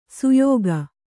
♪ suyōga